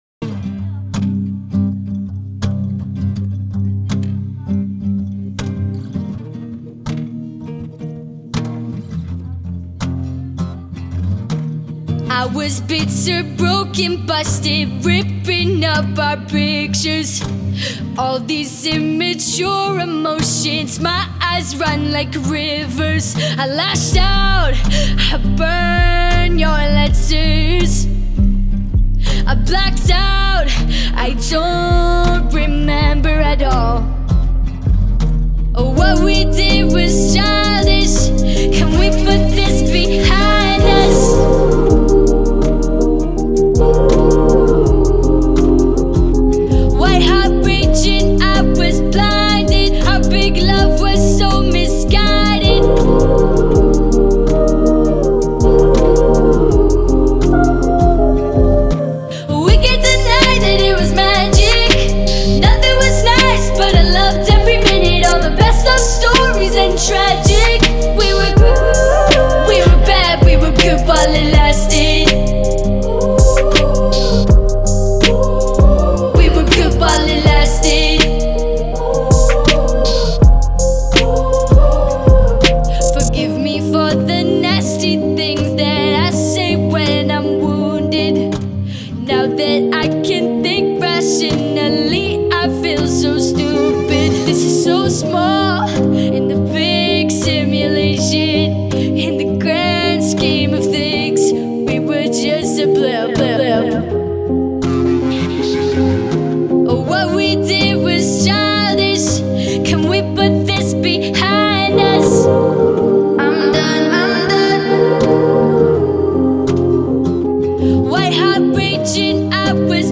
энергичная поп-панк композиция